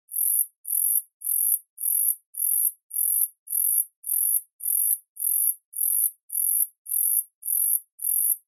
Звуки кузнечиков
3. Трель кузнечика